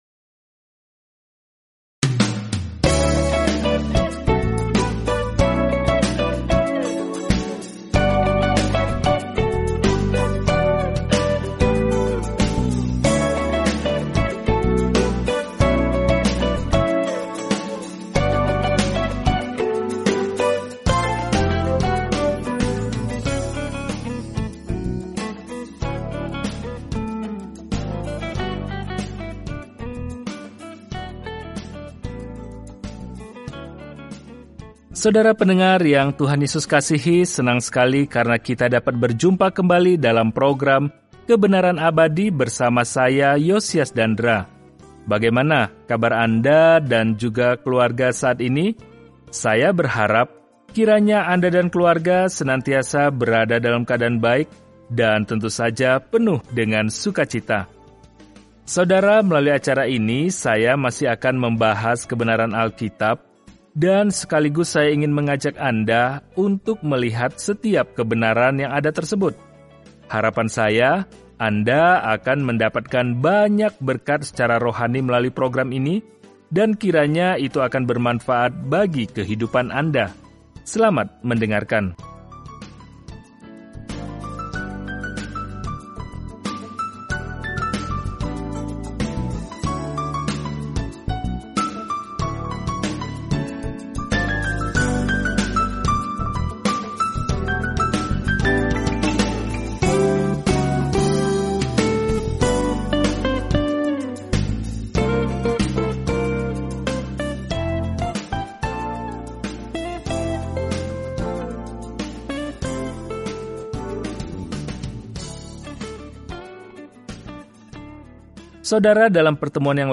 Firman Tuhan, Alkitab Maleakhi 3:5-7 Hari 10 Mulai Rencana ini Hari 12 Tentang Rencana ini Maleakhi mengingatkan Israel yang terputus bahwa dia memiliki pesan dari Tuhan sebelum mereka mengalami keheningan yang lama – yang akan berakhir ketika Yesus Kristus memasuki panggung. Jelajahi Maleakhi setiap hari sambil mendengarkan pelajaran audio dan membaca ayat-ayat tertentu dari firman Tuhan.